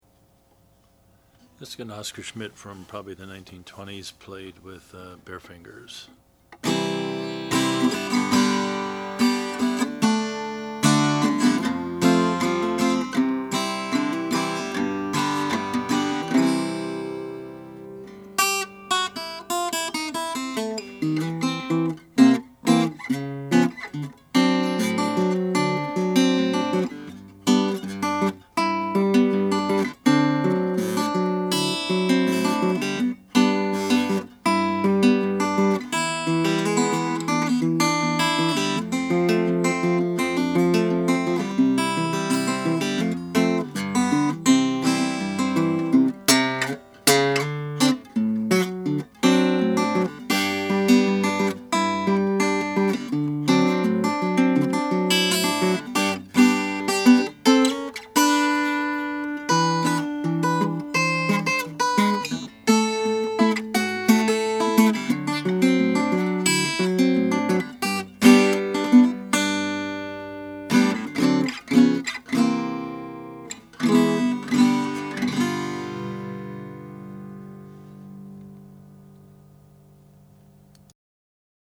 Oscar Schmidt Concert-size Acoustic Guitar c 1920 | $1875 | (vbg#2019) We're always excited to offer top-of-the-line 'Stella' guitars because they don't come around that often, but when they do, you get to see what the Schmidt factory in Jersey City could produce when they pulled out all the stops.
The tone produced when played is not the harsh 'bark' one would expect from a birch-body Stella, but rather a more refined tone, with each string blending well with the other; a great finger picking guitar! When played with picks, the sound projects well without breaking up.